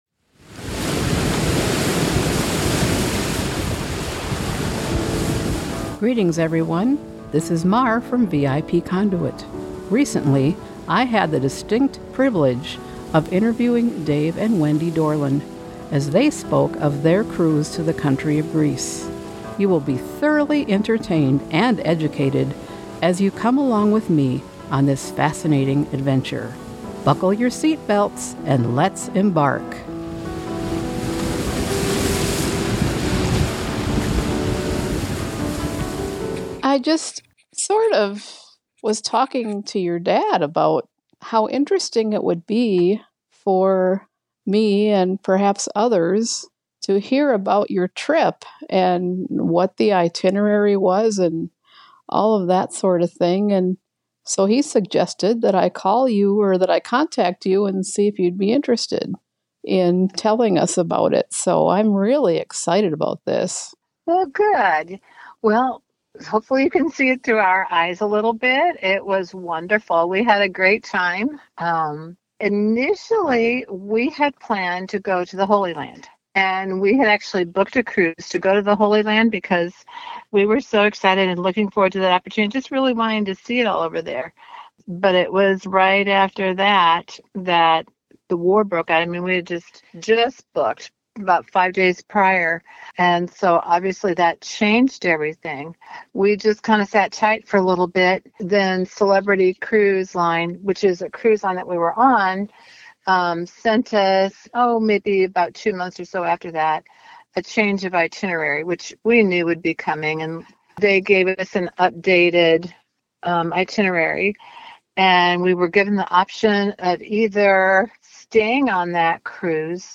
Interesting Interviews Links